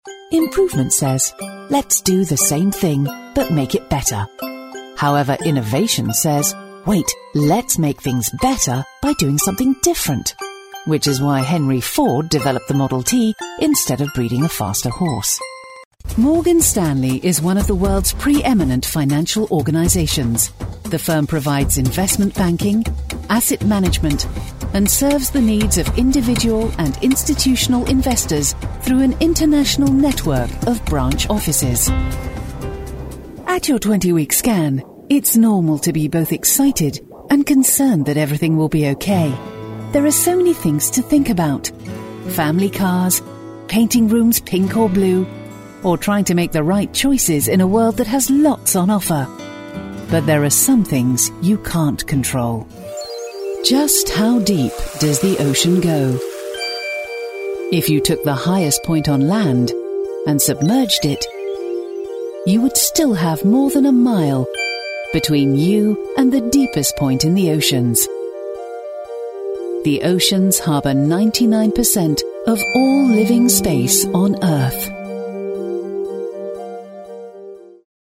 UK British Female Voiceover
CORPORATE